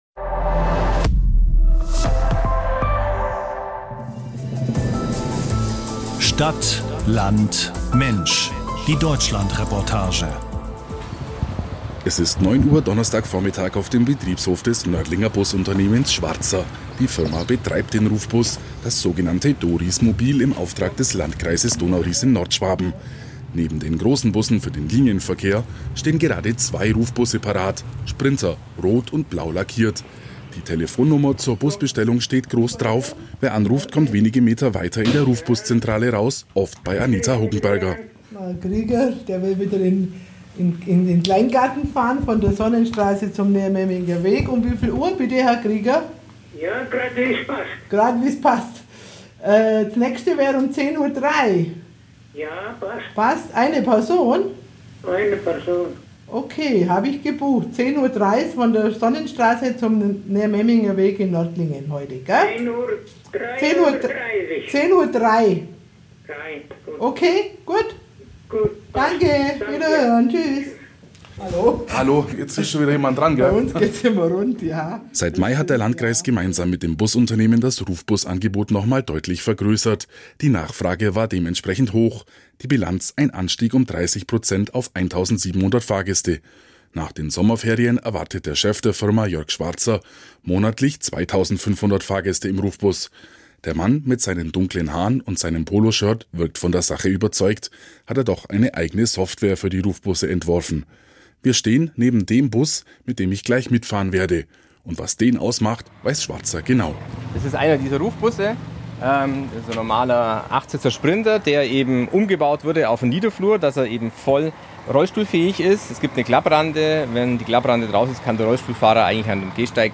Bericht über DoRies mit WEBmonility in der ARD Deutschlandreportage